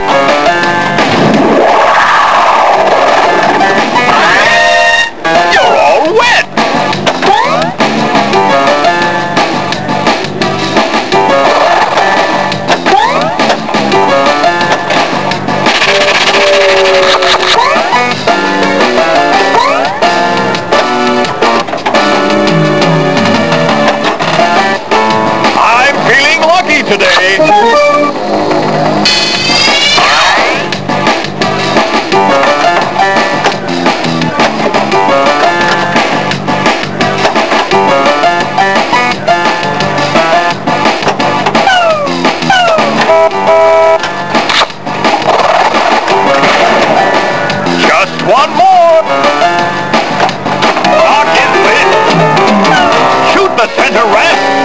Download 592Kb Tema fra spillet